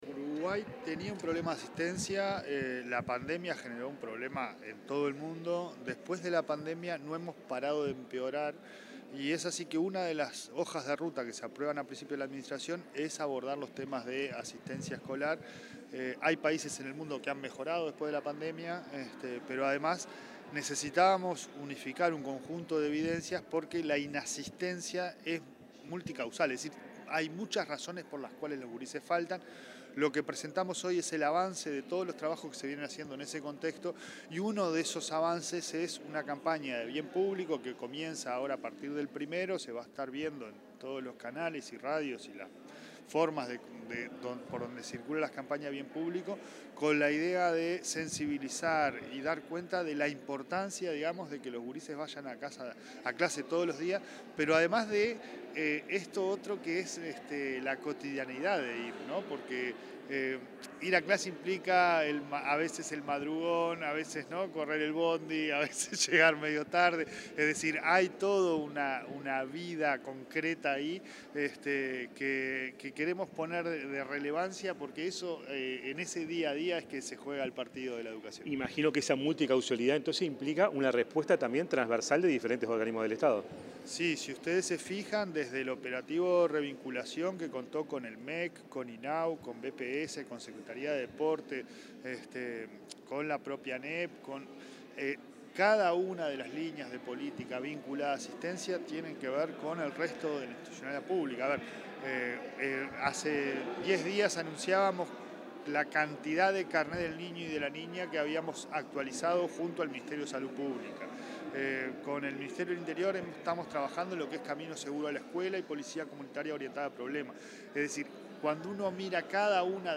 Declaraciones del presidente de la ANEP, Pablo Caggiani
Declaraciones del presidente de la ANEP, Pablo Caggiani 29/09/2025 Compartir Facebook X Copiar enlace WhatsApp LinkedIn Con motivo de la presentación de una campaña informativa y de concientización sobre la importancia de que los estudiantes asistan a clases de forma regular, el presidente de la Administración Nacional de Educación Pública (ANEP), Pablo Caggiani, realizó declaraciones a la prensa.